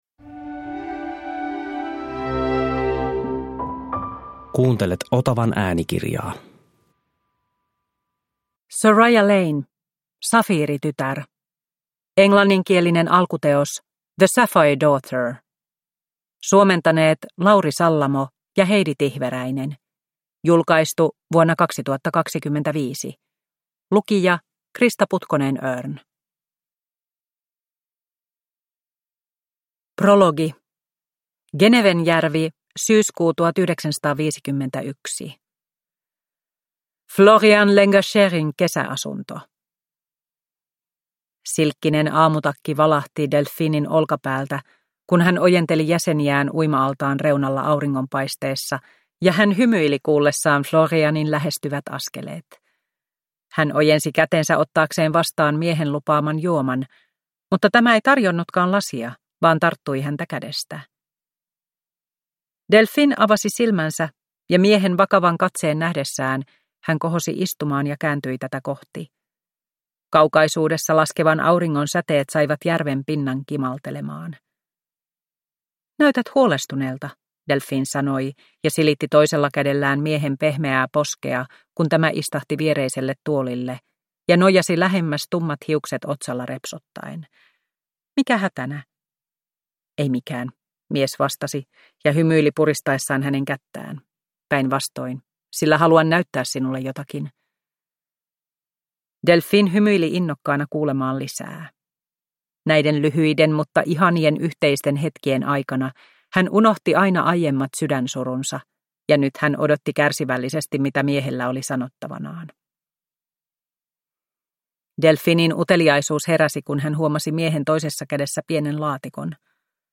Safiiritytär (ljudbok) av Soraya Lane